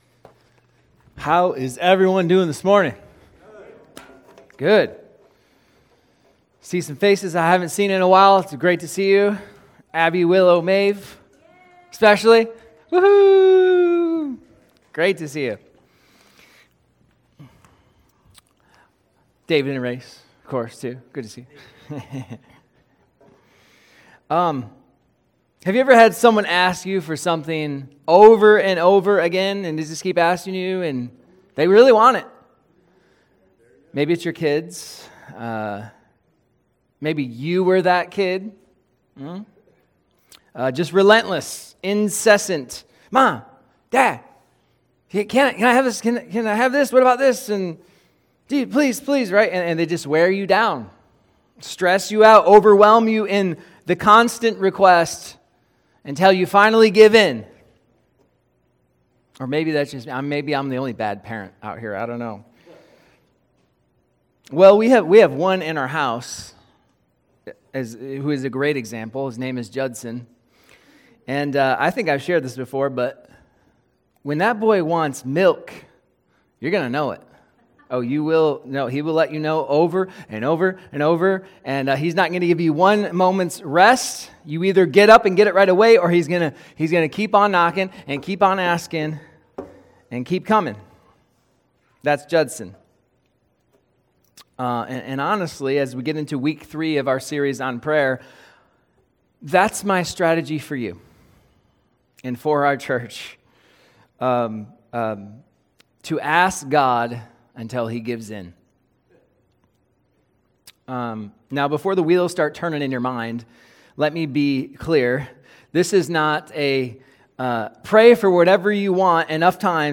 Sermons | Damascus Church